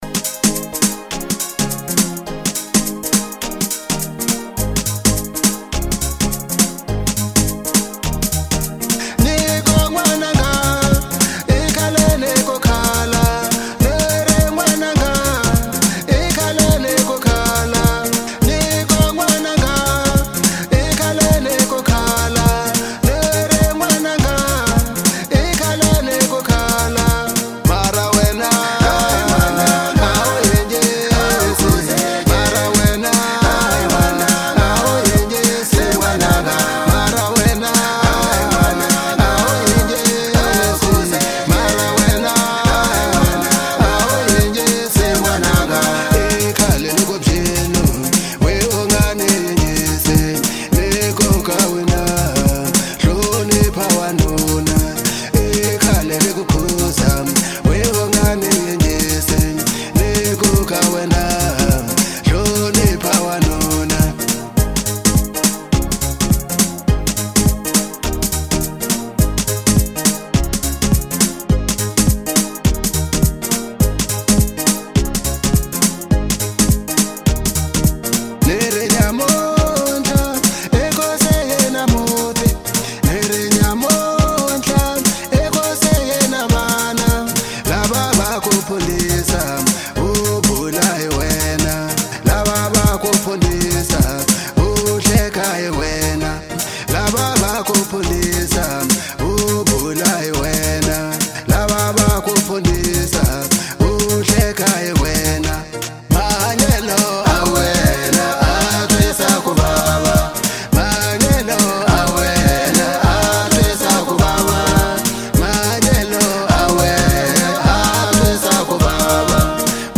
04:58 Genre : African Disco Size